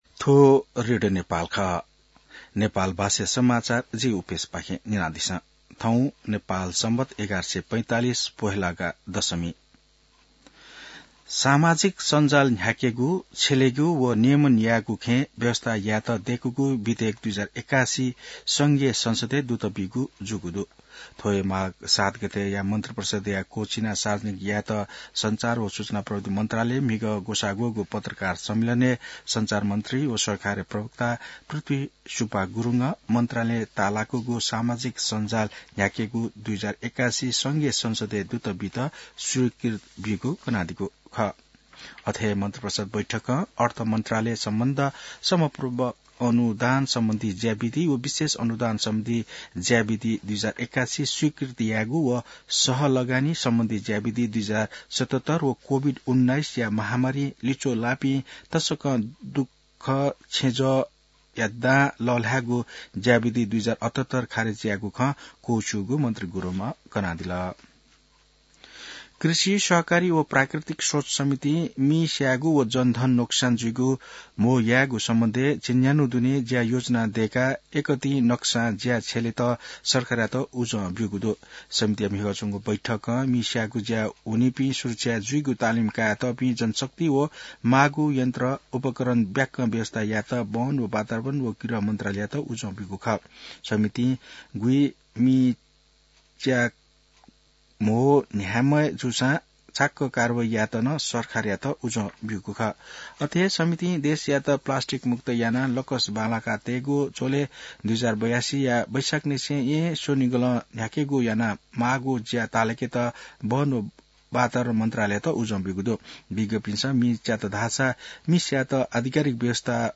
नेपाल भाषामा समाचार : ११ माघ , २०८१